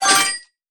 SFX_delivery_success02.wav